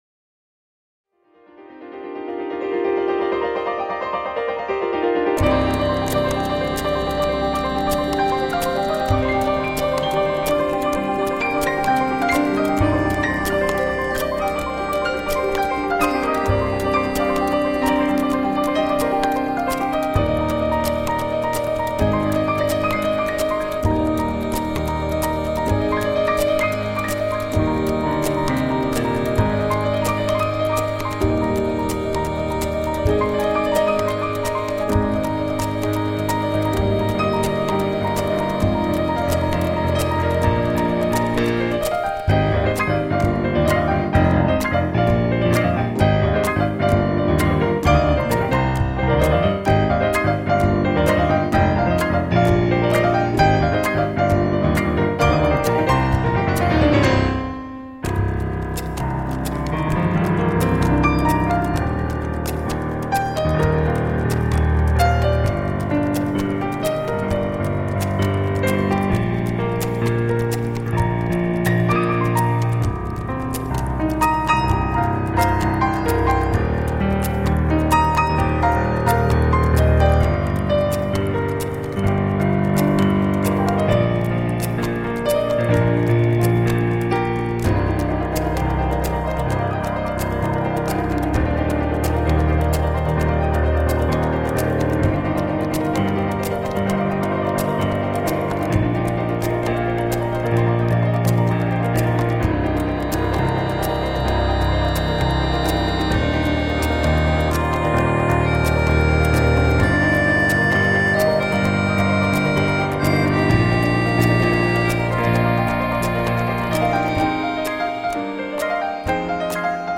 A secret garden of lucid musical dreams .
Tagged as: New Age, Instrumental New Age, Contemporary Piano